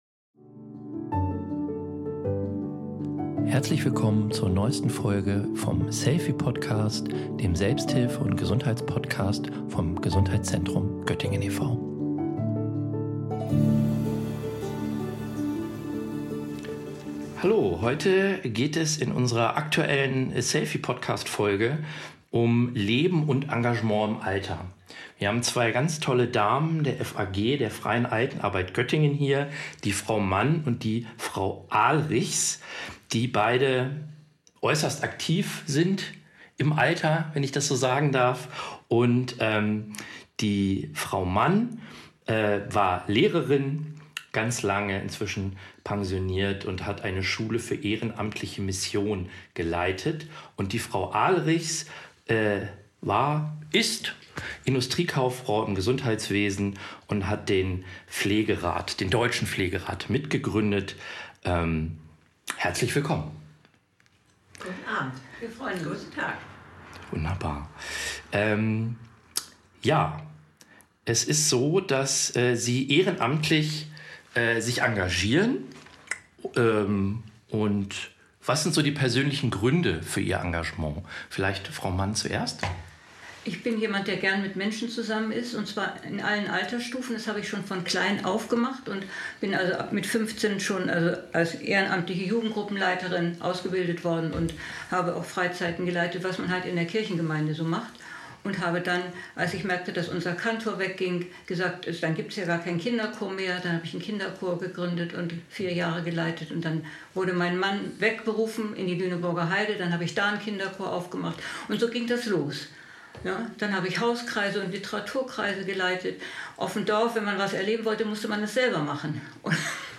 In unserem Podcast bieten wir wertvolle Einblicke und Informationen zu den großen Themen der Gesundheit und Medizin. Wir sprechen mit Experten aus der Wissenschaft sowie mit Betroffenen, um Ihnen fundiertes Wissen und persönliche Erfahrungen näherzubringen.